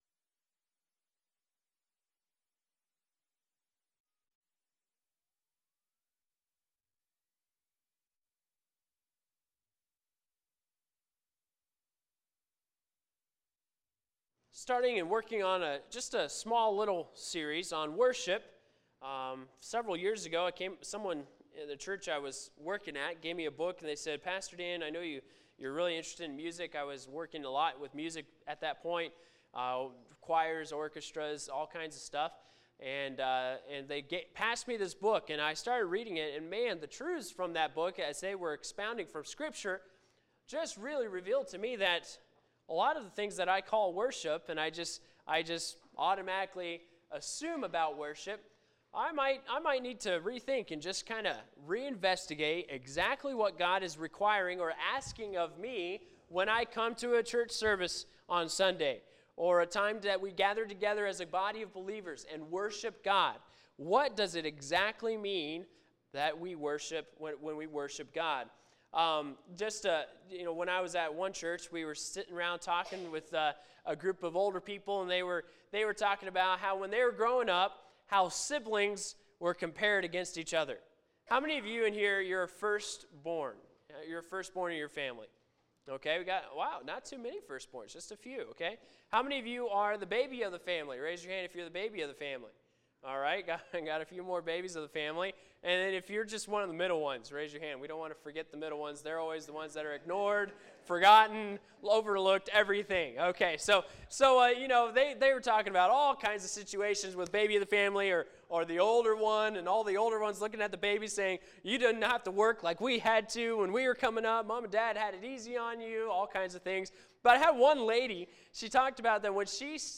Sermons | Anthony Baptist Church